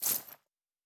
Coin and Purse 06.wav